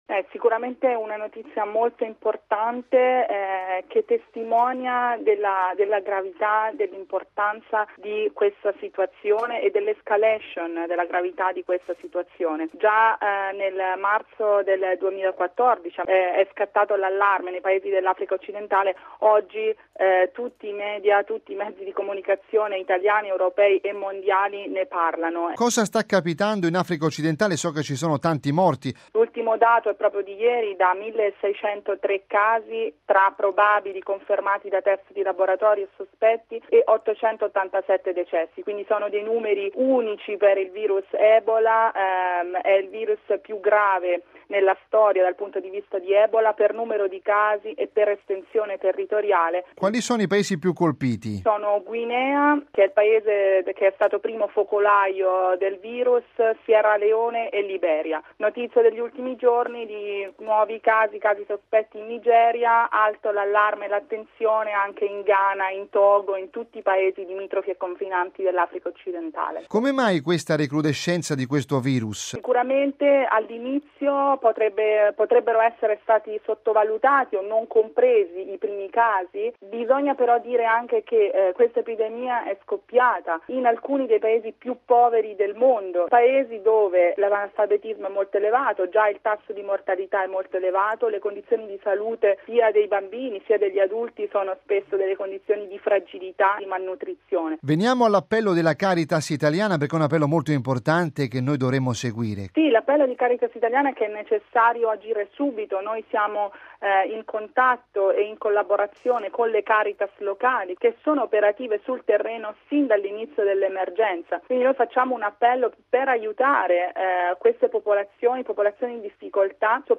raggiunta al telefono